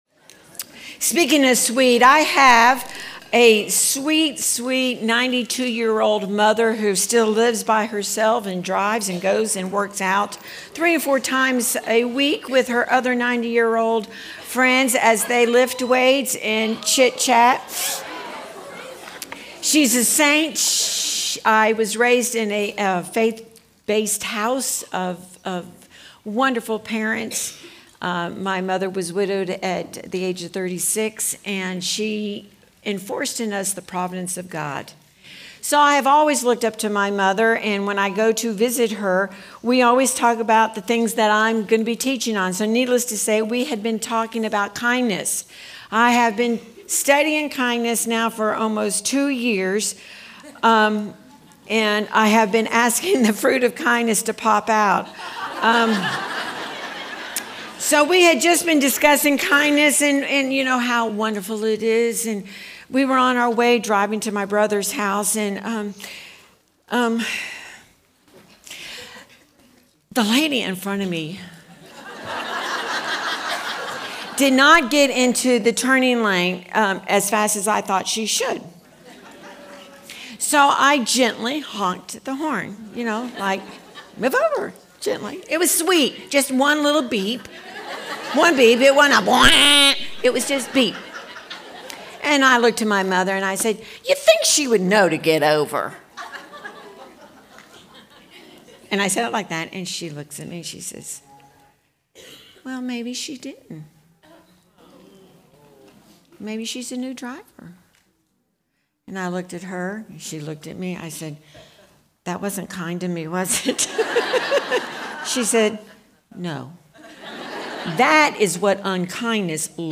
Home » Sermons » Fruit of Kindness